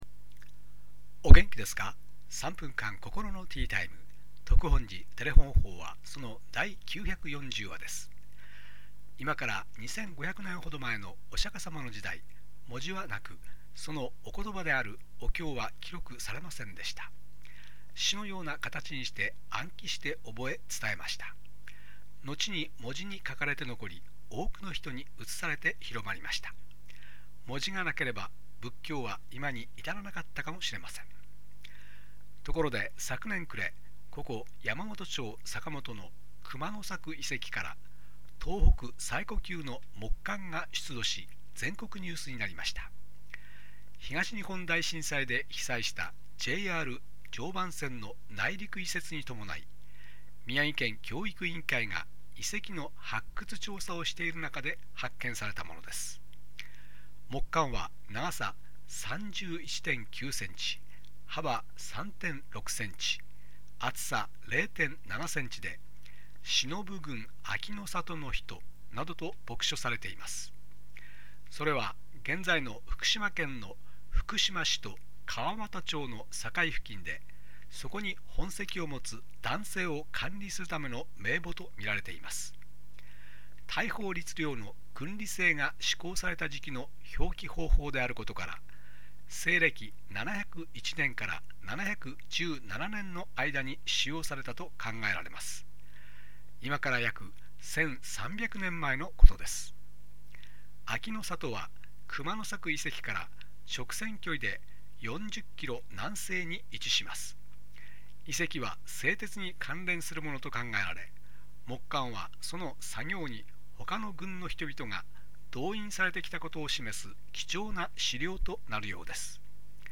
テレホン法話
住職が語る法話を聴くことができます